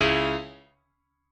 admin-leaf-alice-in-misanthrope/piano34_1_026.ogg at a8990f1ad740036f9d250f3aceaad8c816b20b54